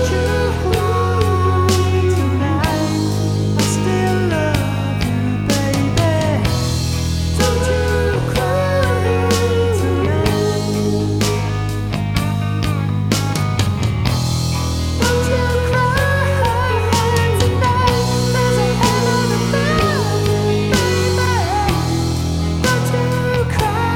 Minus Lead Guitar Rock 4:21 Buy £1.50